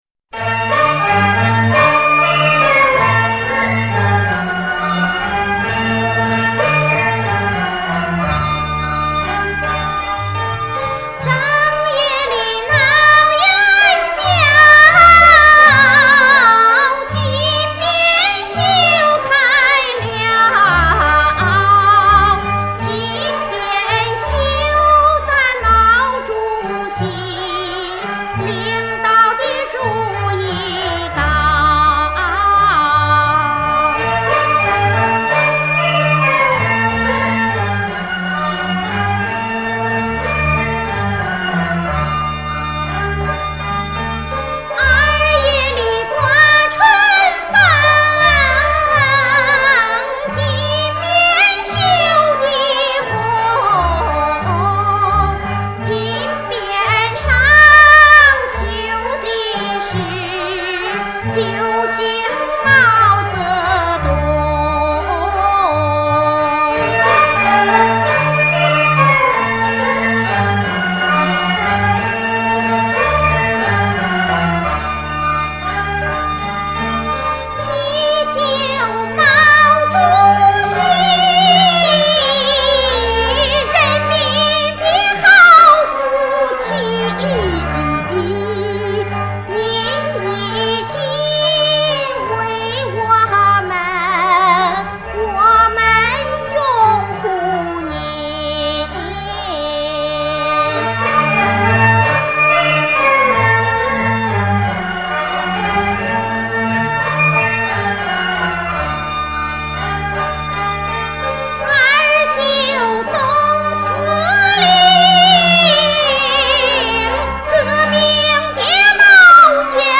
陕北民歌
歌曲结构短小，全曲共上下两乐句，下句的曲调骨架是上句的下四度变化模进。旋律的进行明快流畅，起伏自然，层次分明，结构严谨。